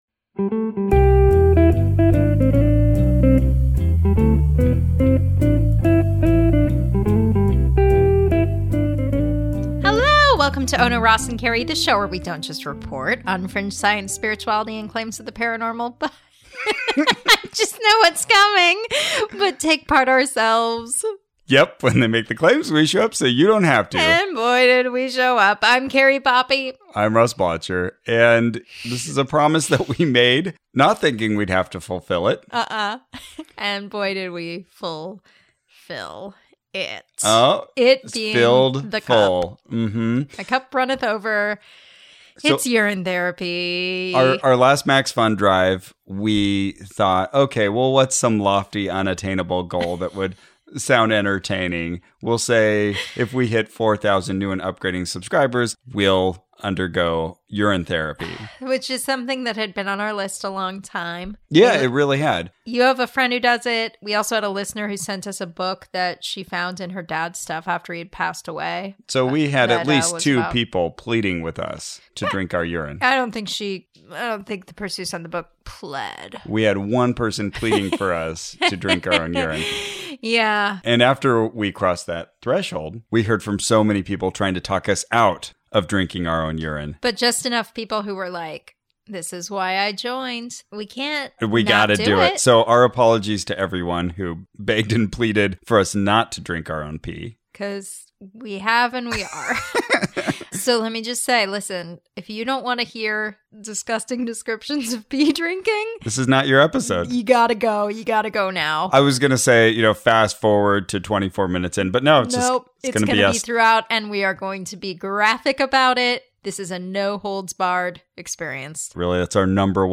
Join us as we read two books about an ancient therapy, then improve our general health with nature's perfect medicine: our own pure, unadulterated urine. Fair warning: episode contains disgusting descriptions, disgusting sounds, and liters of pee.